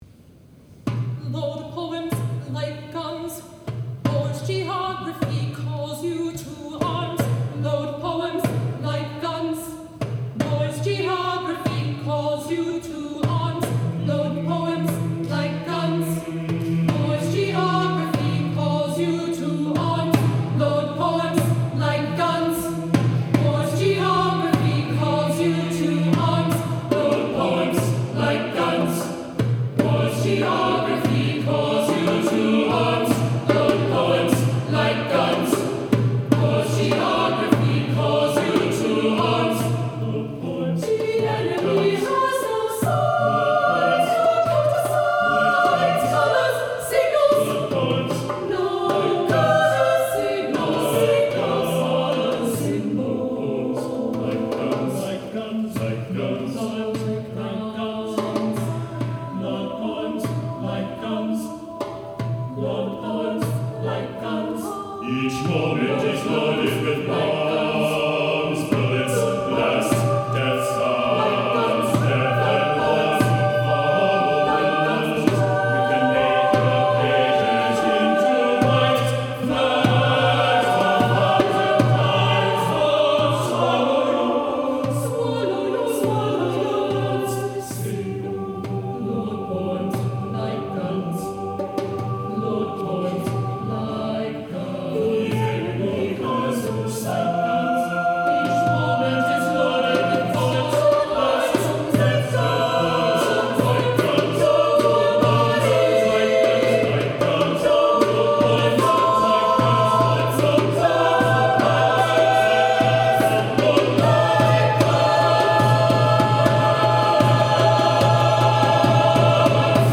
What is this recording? SATB, percussion